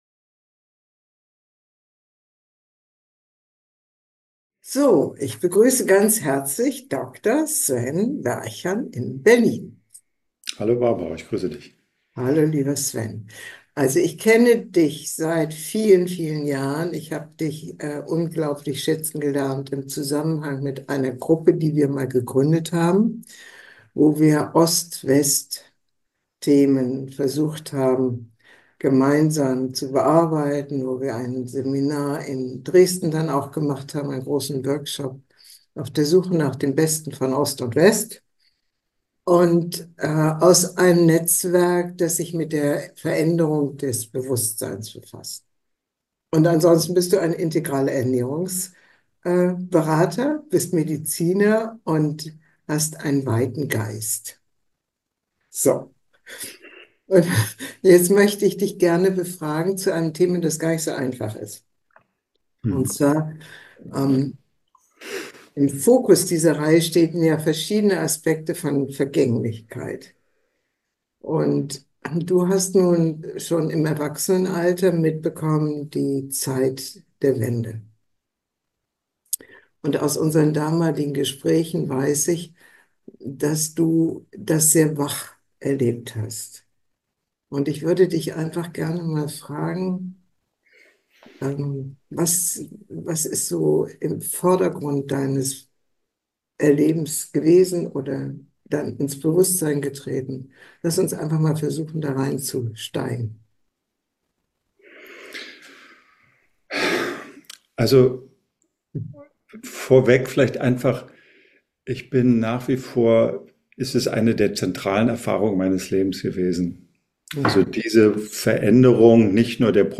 128 Raum weiten - jenseits von richtig und falsch. Interview